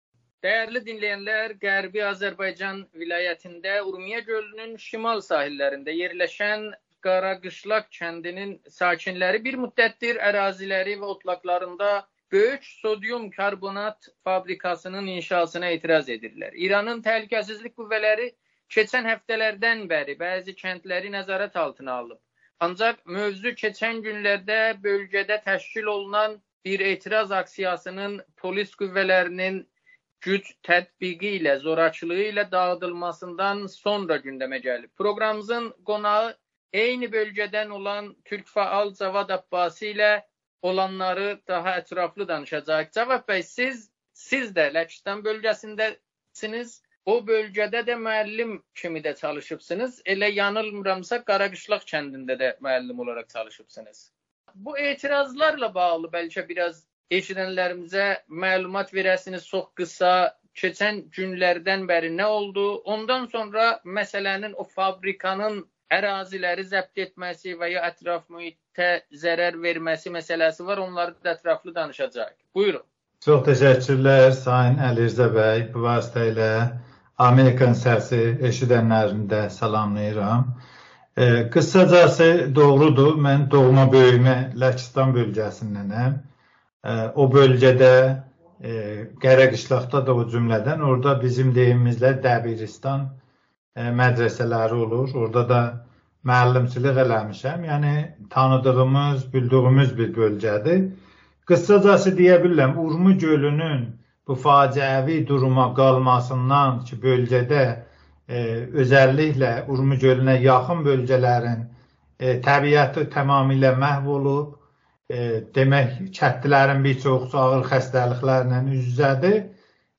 Amerikanın Səsinə müsahibədə...